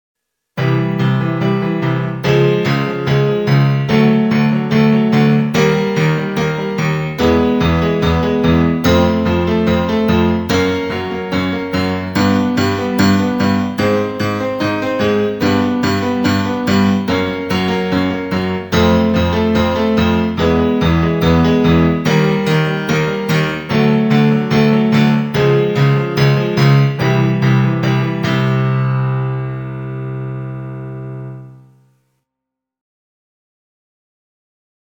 02-EXERCICIO-BRU_-vibrando-os-labios_em-volume-reduzido.mp3